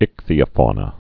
(ĭkthē-ə-fônə)